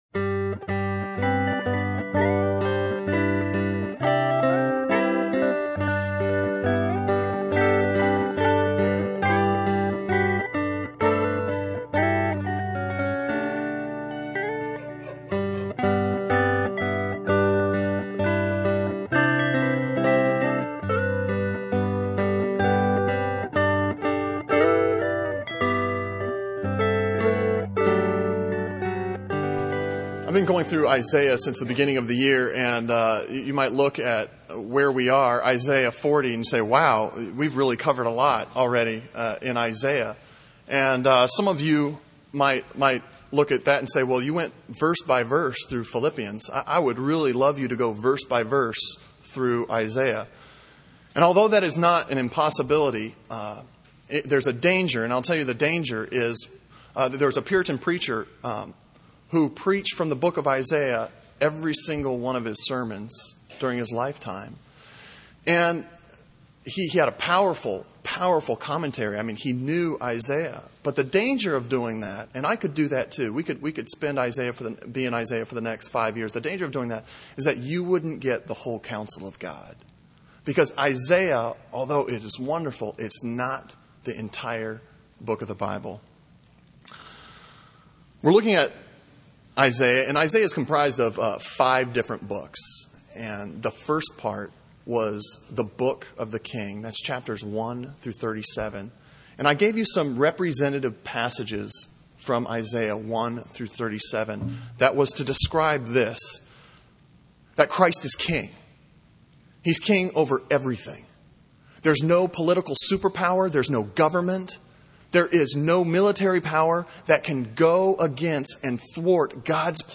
Sermon on Isaiah 40:1-8 from February 4